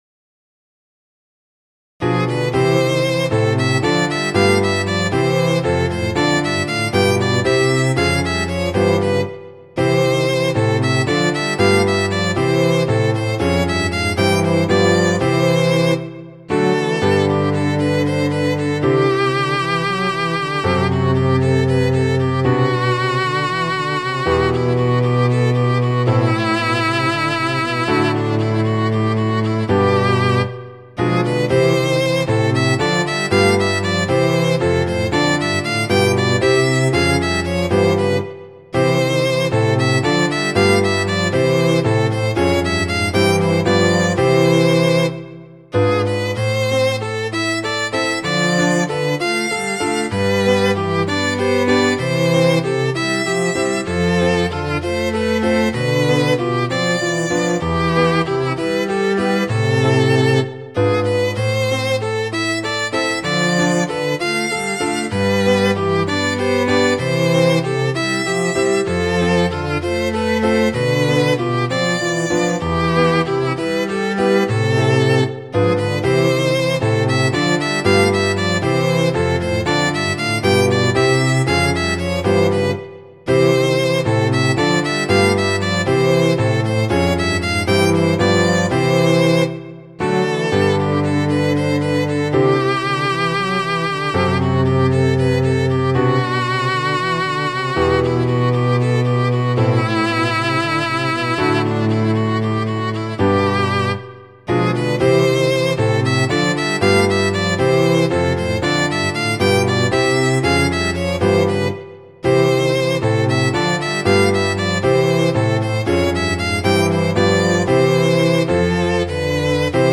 そういう訳でインスト曲ばかりですが、このページでは2009年に作曲した自作の曲を公開しています。
冬の暖かい日の気分をそのまま曲にしたような感じの曲です。
• 楽器：ヴァイオリン、チェロ、ピアノ
• 主調：ハ長調
• 拍子：7/8拍子、6/8拍子
• 速度：八分音符＝232、八分音符＝192
• 楽式：大ロンド形式